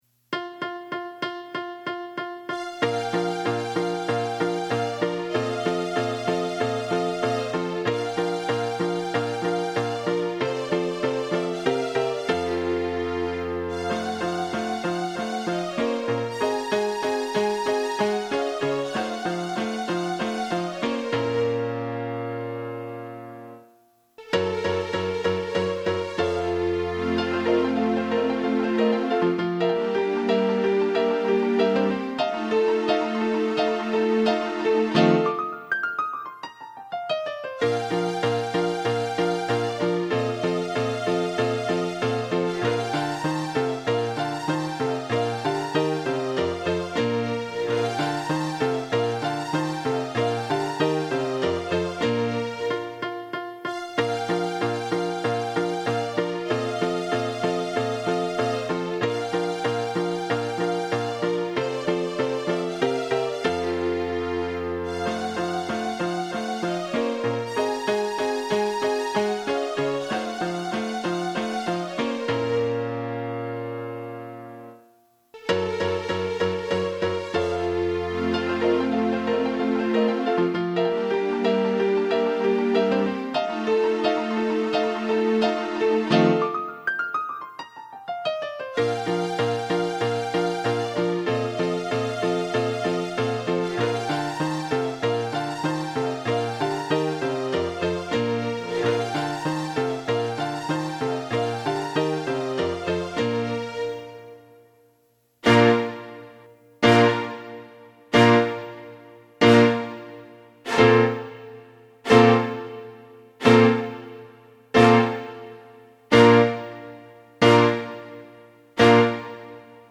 So I dusted the keyboard and learned it in a couple weeks. Also knowing that since I have like 6 other projects I want to do [it's my hobby] I can't spend months learning how to play the song good enough from front to back so I recorded it in segments and then did the ol cut n paste.
I split the difference and recorded the fast part at 190 beats per minute and the slow part just 10 beats per minute slower.